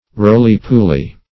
Search Result for " rolly-pooly" : The Collaborative International Dictionary of English v.0.48: Rolly-pooly \Roll"y-pool`y\, n. [Etymol. uncertain.]